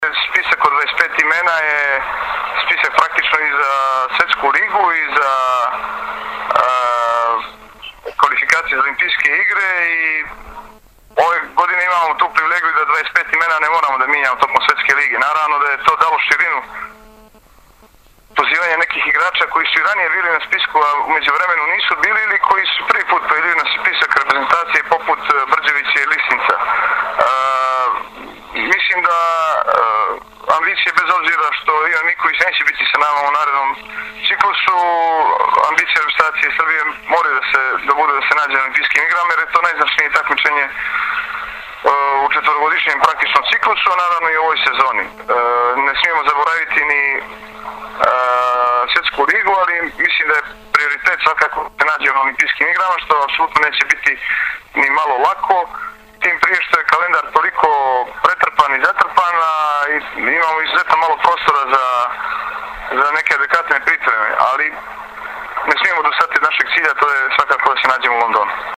IZJAVA IGORA KOLAKOVIĆA 1